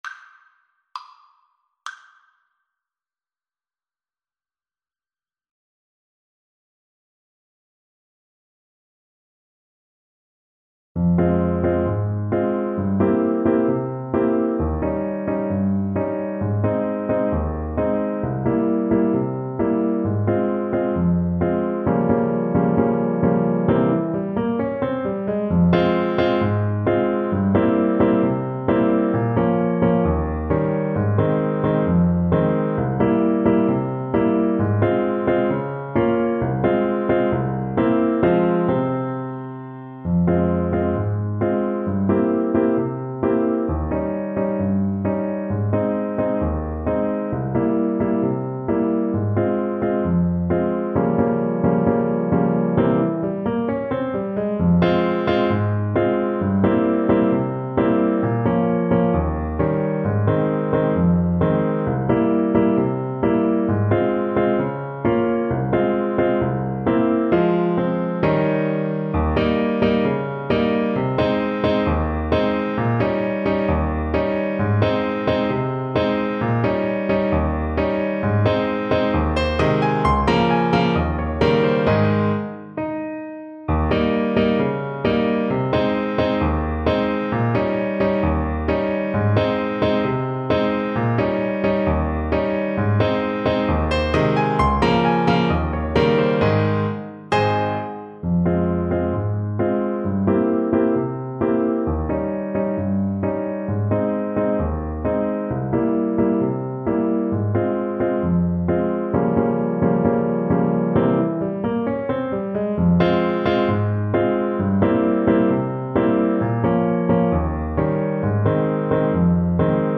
Trumpet version
2/4 (View more 2/4 Music)
Slow =c.66 = 66
Classical (View more Classical Trumpet Music)